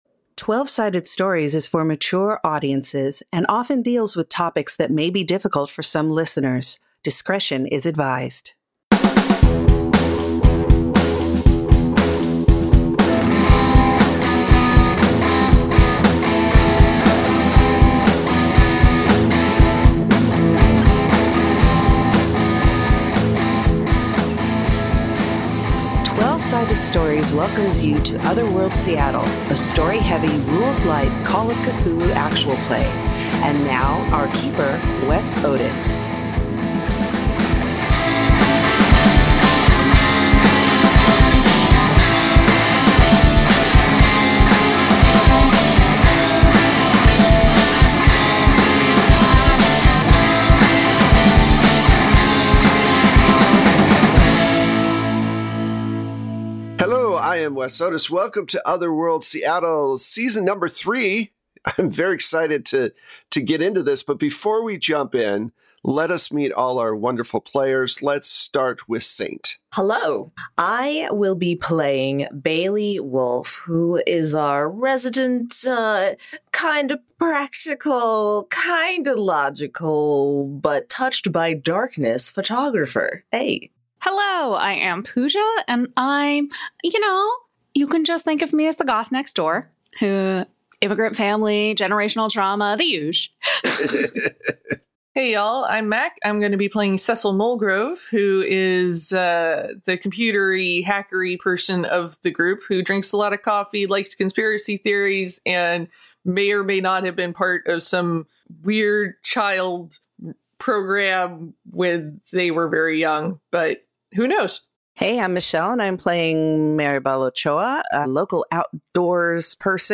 Other World Seattle Ep21 (A Call Of Cthulhu Actual Play) Twelve-Sided Stories podcast To give you the best possible experience, this site uses cookies.
Audio Drama